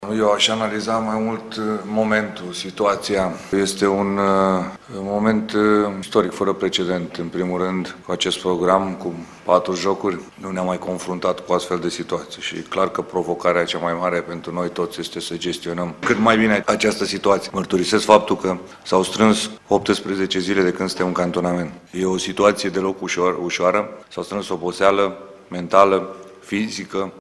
Edi Iordănescu afirmă într-o conferință de presă că partida va fi una dificilă.